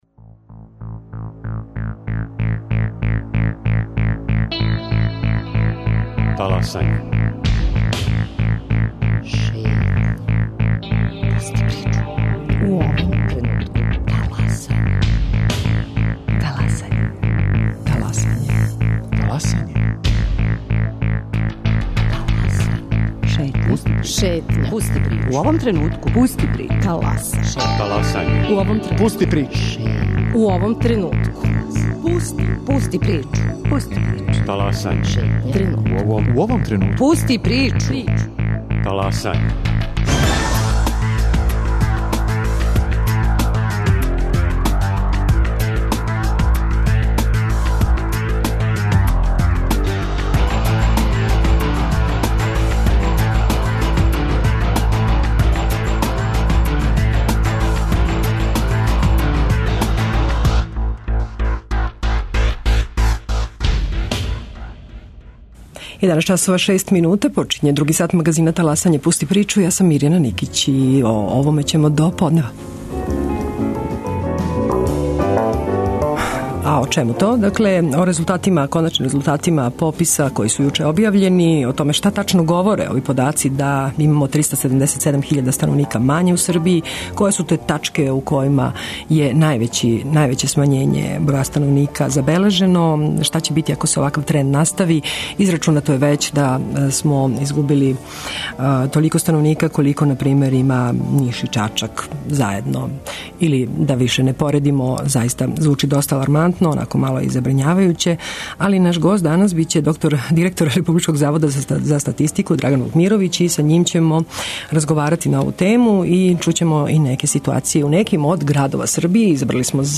Како ће изгледати демографска карта Србије ако се овај тренд настави? Гост Таласања је Драган Вукмировић, директор Републичког завода за статистику.